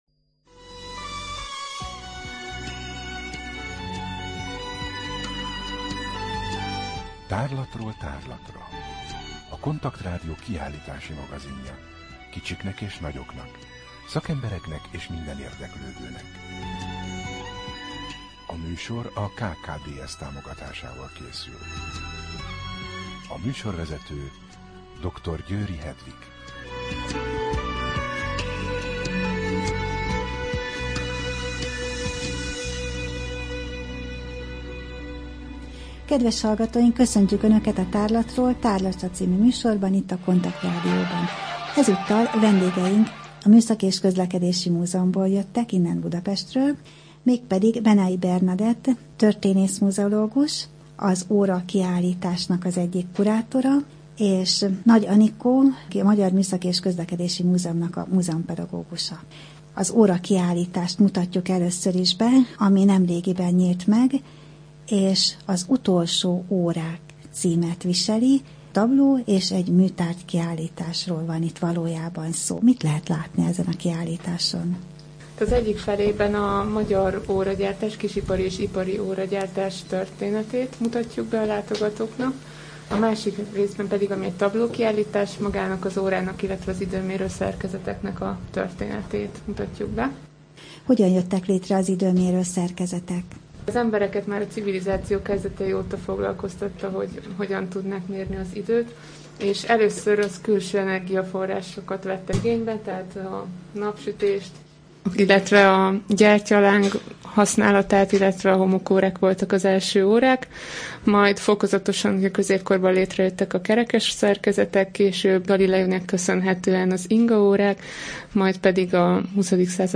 Rádió: Tárlatról tárlatra Adás dátuma: 2015, March 26 Tárlatról tárlatra / KONTAKT Rádió (87,6 MHz) 2015. március 26. A műsor felépítése: I. Kaleidoszkóp / kiállítási hírek II. Bemutatjuk / Az utolsó Órák – Magyar Műszaki és Közlekedési Múzeum, Budapest A műsor vendége